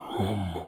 Audio / SFX / Characters / Voices / LegendaryChef / LegendaryChef_03.wav